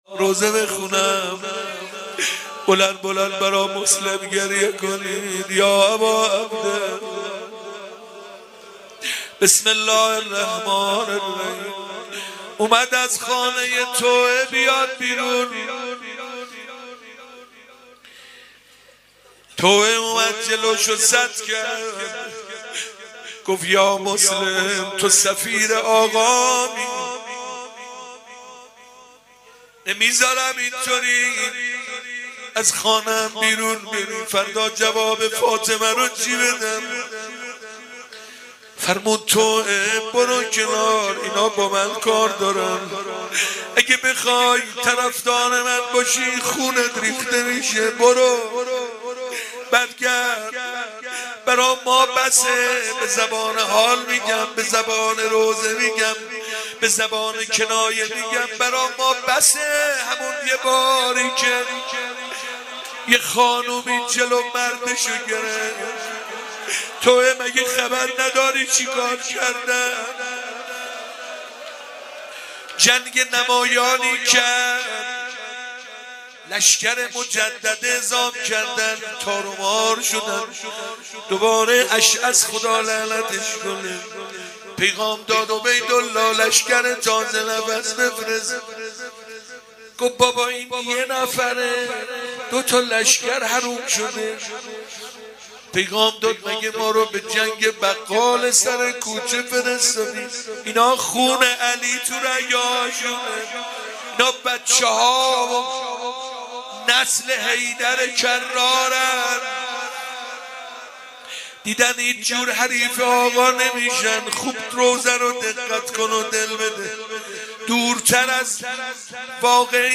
روضه حضرت مسلم